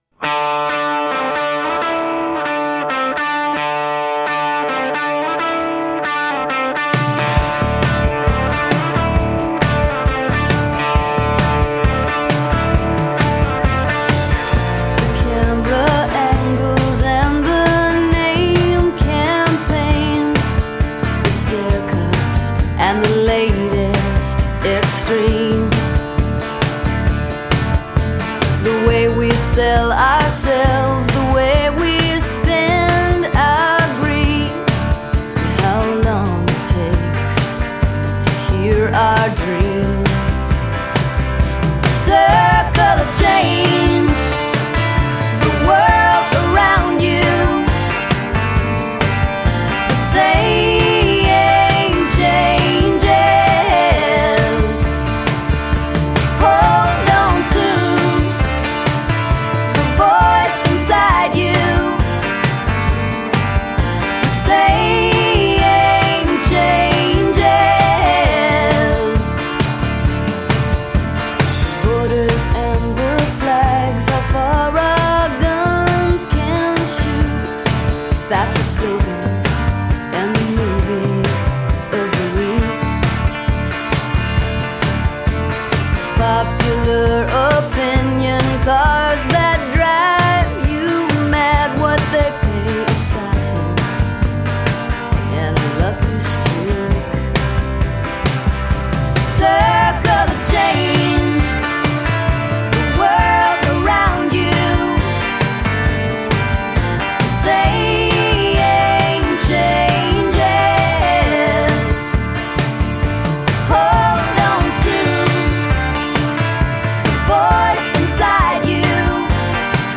strong hooks, a jangle-pop sound
guitar